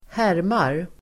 Uttal: [²h'är:mar]